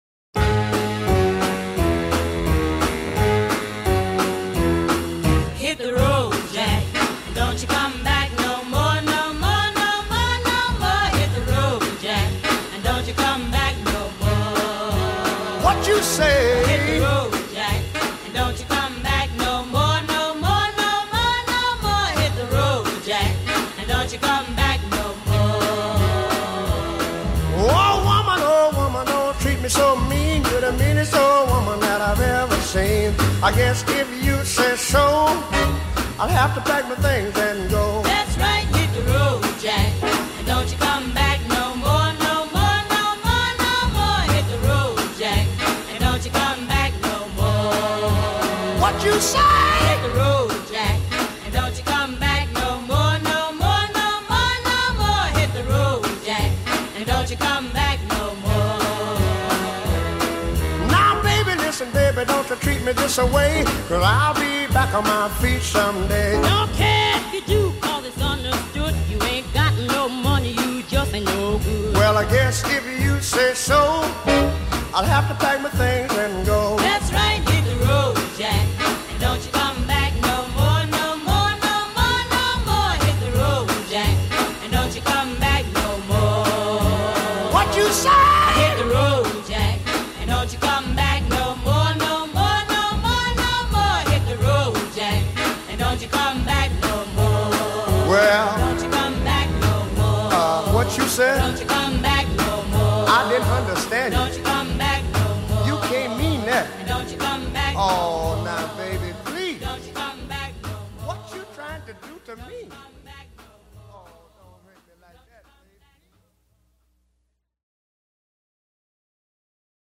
Some creative singing.